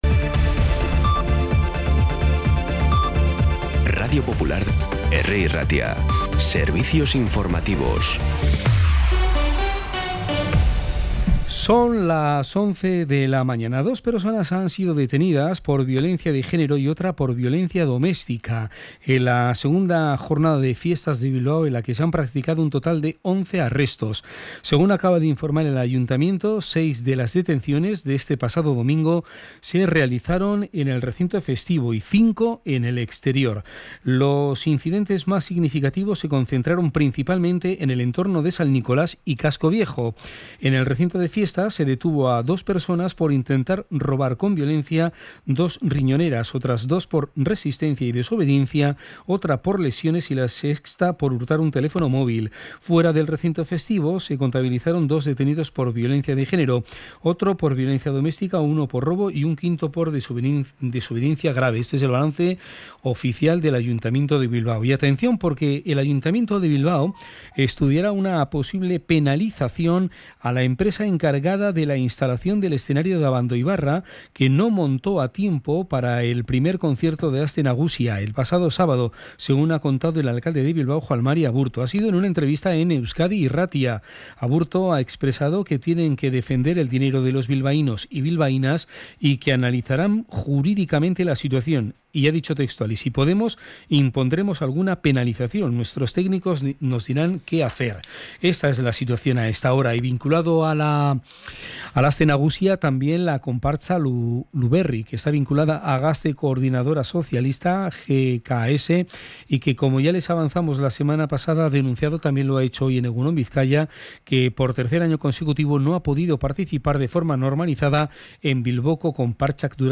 Las noticias de Bilbao y Bizkaia del 18 de agosto a las 11
Los titulares actualizados con las voces del día.